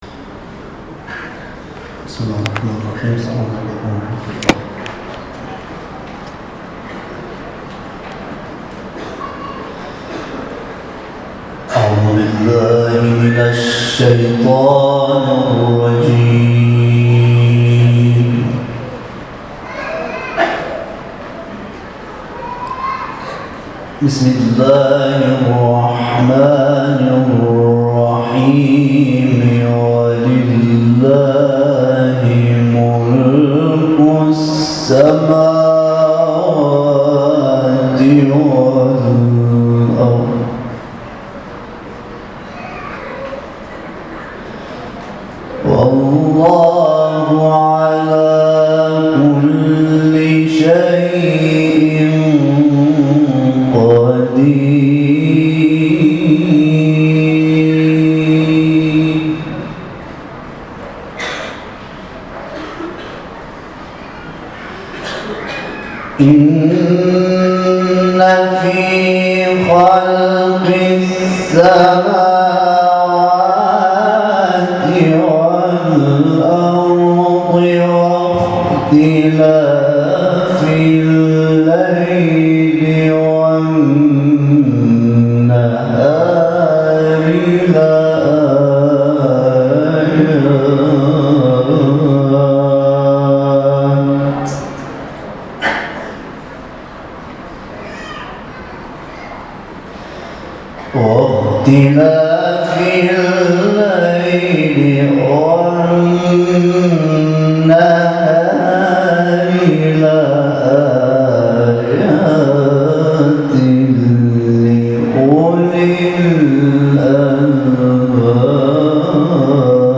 تلاوت قاری افغان در محفل «مشکوة‌النور»